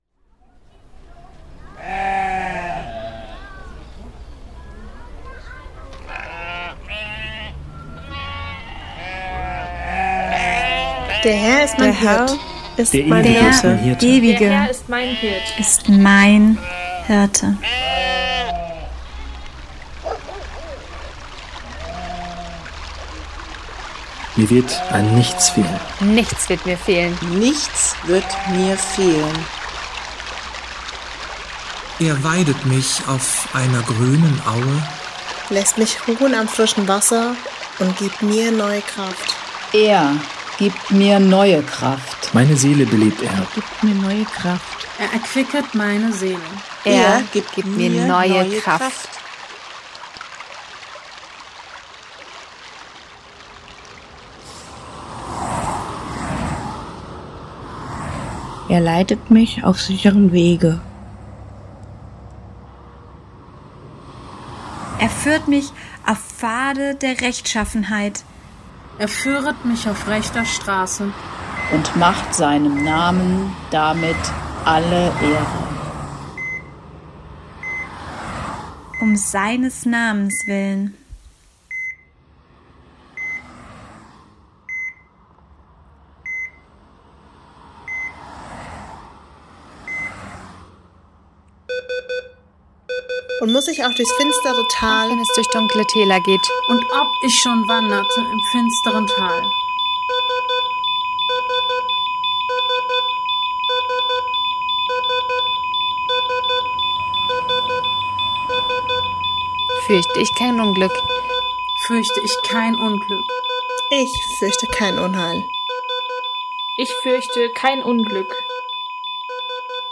Verschiedene Stimmen verweben sich mit Soundflächen, Geräuschen und musikalischen Fragmenten zu einem dichten akustischen Teppich.